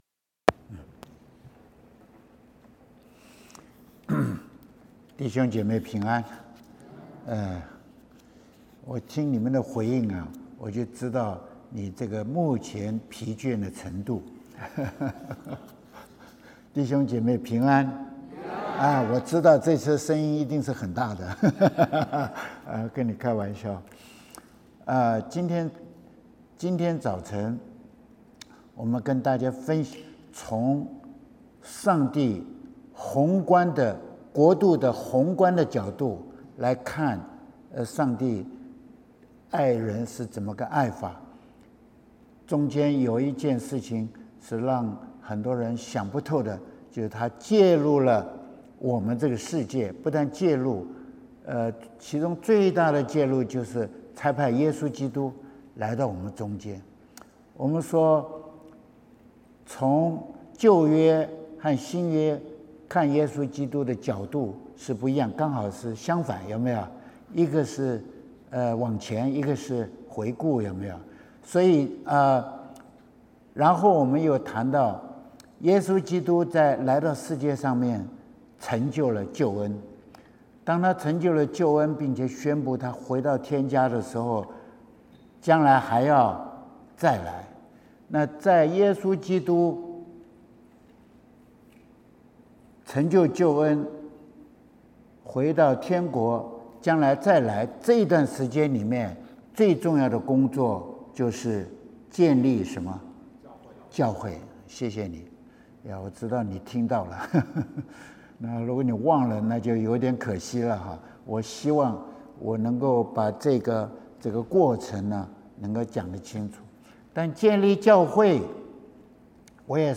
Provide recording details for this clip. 2024 Summer Retreat